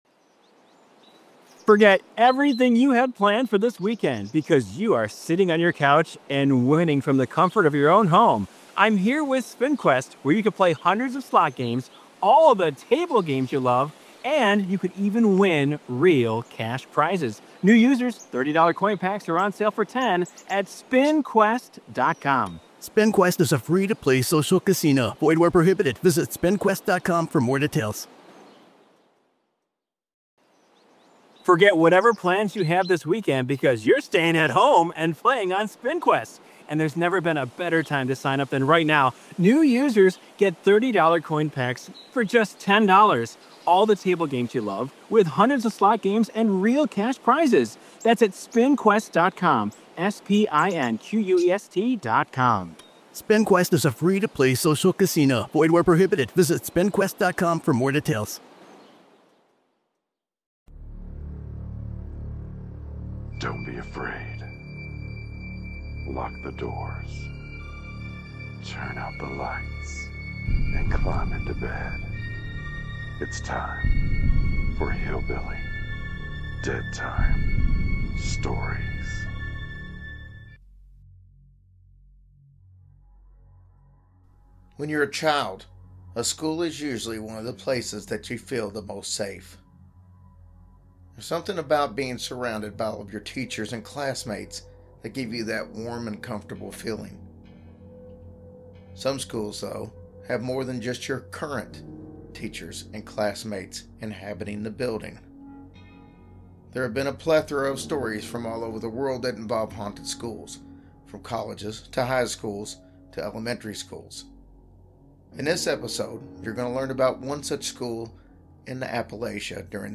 Introduction voice over
Narrated
Closing song